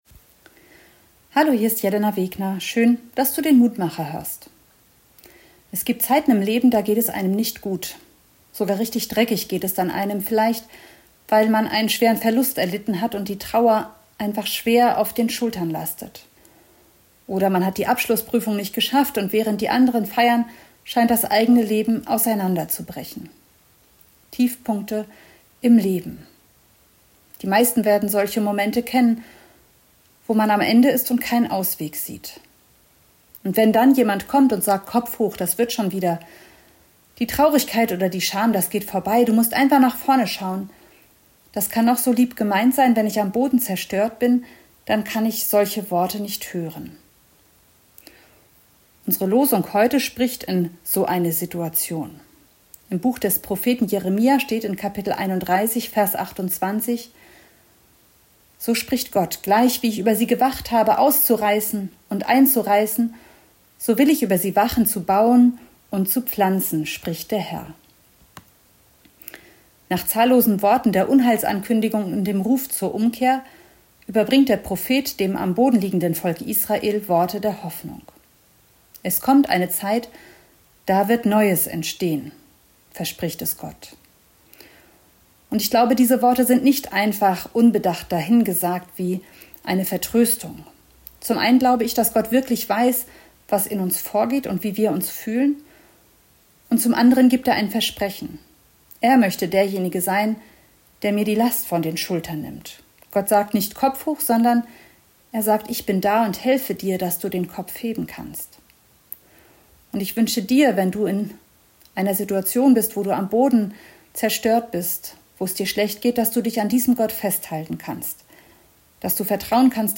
Mit dem Mutmacher hört ihr einen kleinen Impuls und Gedanken zu Losung oder Lehrtext nach den Herrnhuter Losungen. Hört Euch die Andacht an, sprecht mit uns ein Gebet für den Tag.